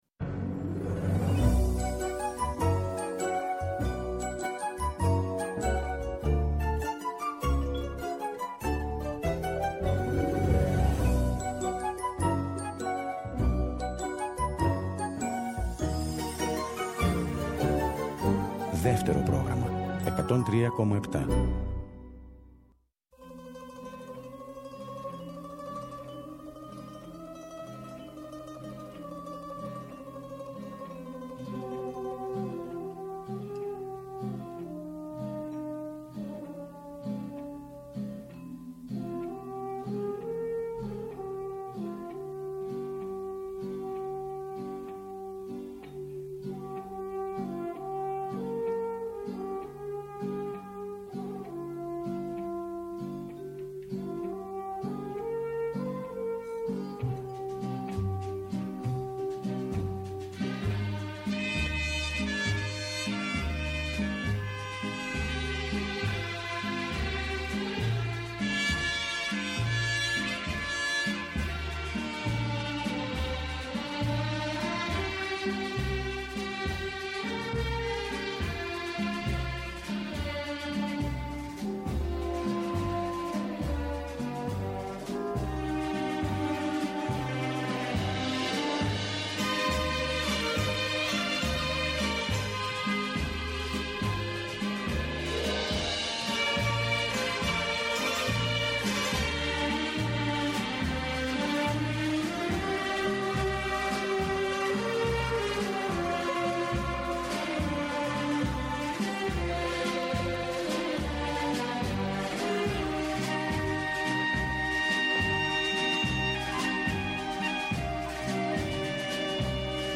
κάθε Σάββατο και Κυριακή στις 19.00 έρχεται στο Δεύτερο Πρόγραμμα με ένα ραδιοφωνικό – μουσικό road trip. ΔΕΥΤΕΡΟ ΠΡΟΓΡΑΜΜΑ